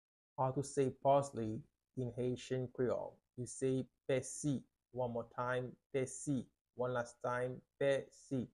How to say "Parsley" in Haitian Creole - "Pèsi" pronunciation by a native Haitian Teacher
“Pèsi” Pronunciation in Haitian Creole by a native Haitian can be heard in the audio here or in the video below: